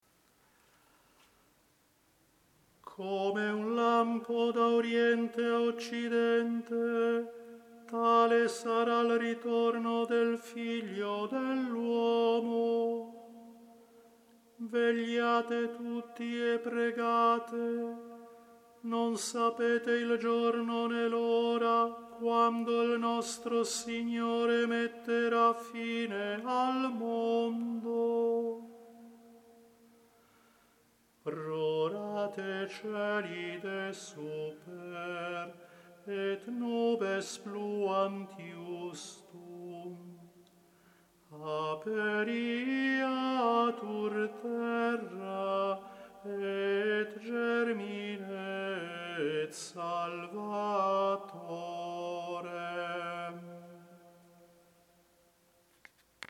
Si propone un tono salmodico ad hoc per ogni domenica di Avvento, che possa ben adattarsi alla semplice e magnifica antifona ambrosiana Rorate Coeli.